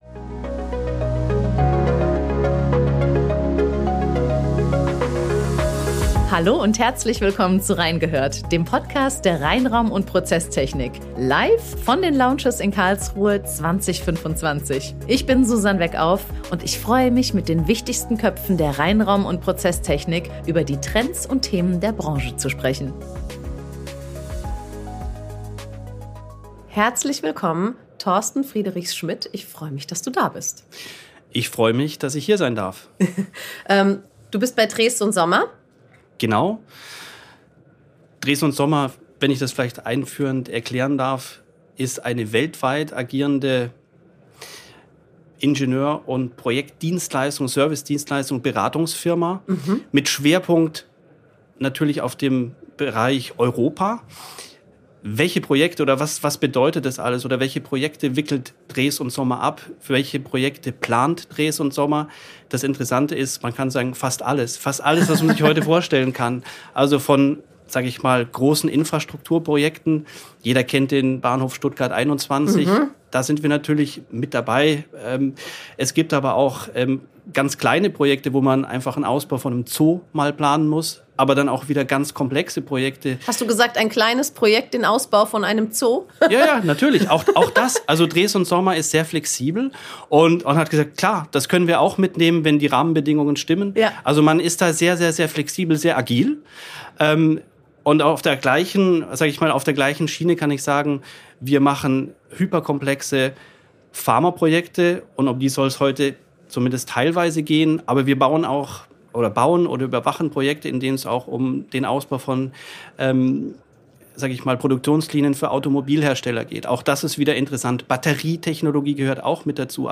Reingehört spezial, live von den Lounges 2025 in Karlsruhe.